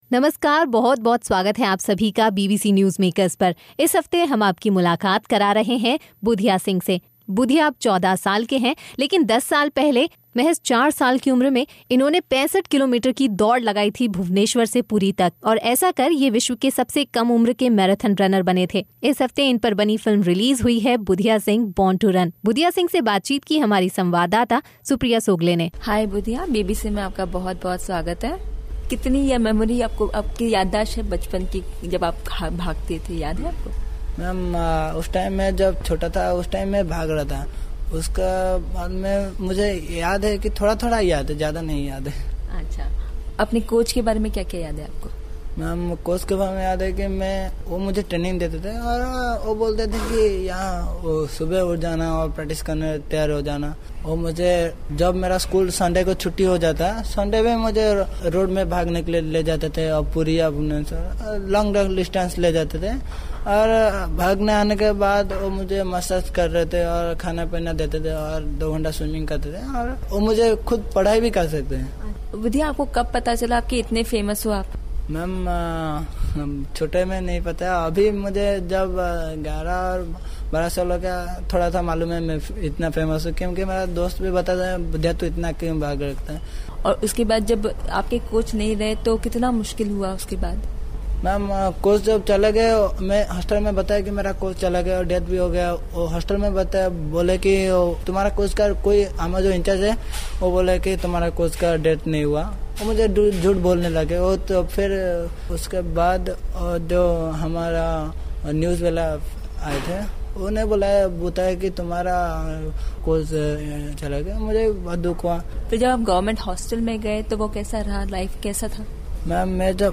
बातचीत